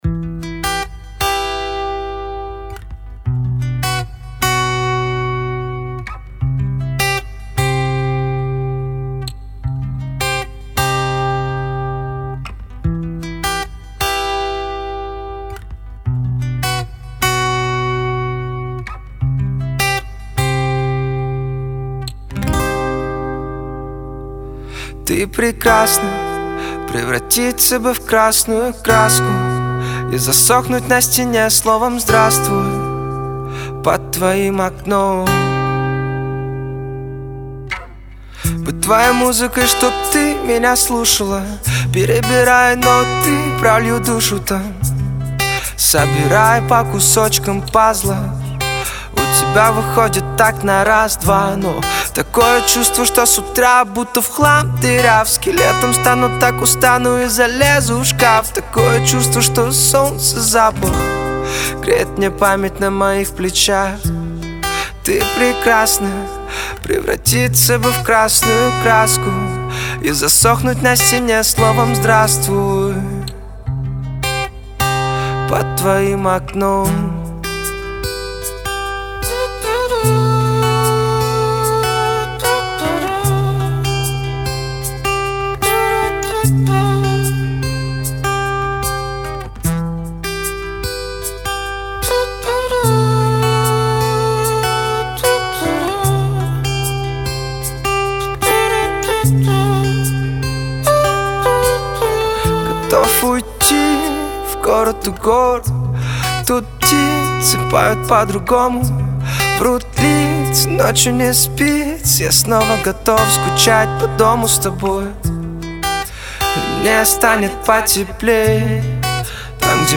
который сочетает в себе элементы инди и поп-музыки.
выразительный вокал